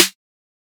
TGOD Snare.wav